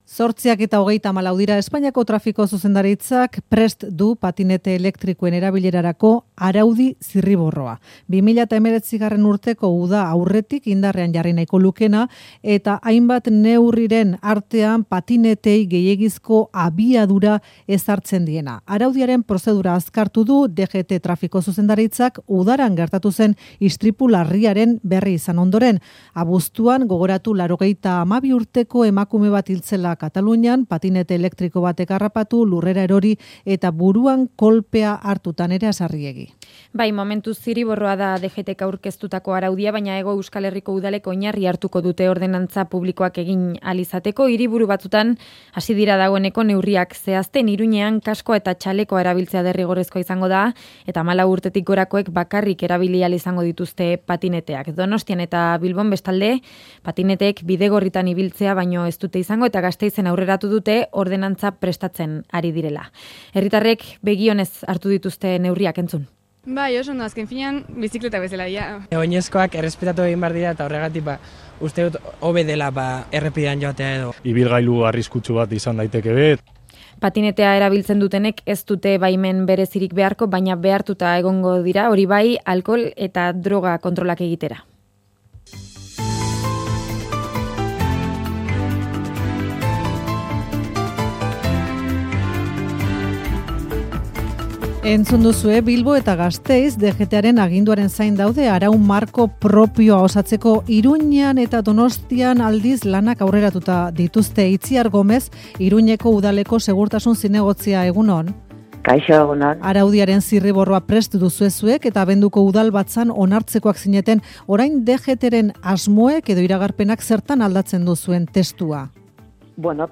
Audioa: Itziar Gomez, Iruñeko segurtasun zinegotzia. Faktoria, Euskadi Irratia.